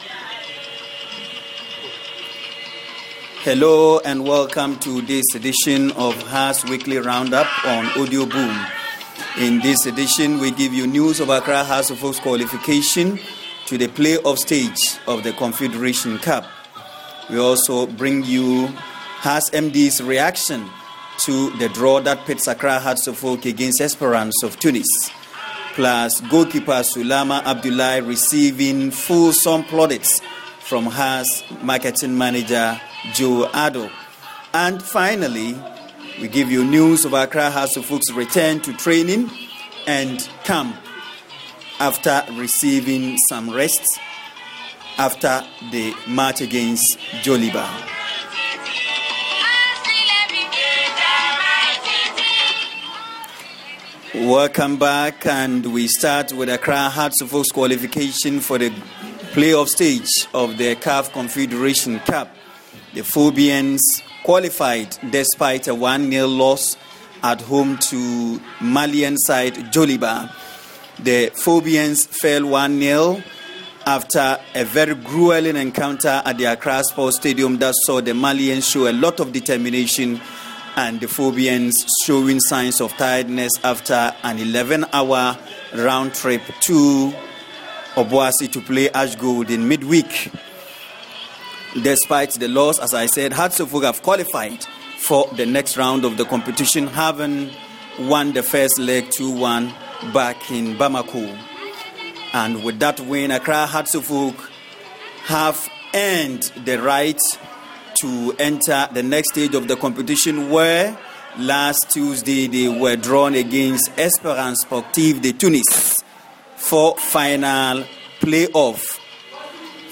Listen to the most comprehensive weekly coverage of Accra Hearts of Oak Sporting Club, this and every Wednesday, for everything that is on-going at the club including latest team news, player and manager interviews, and many more.....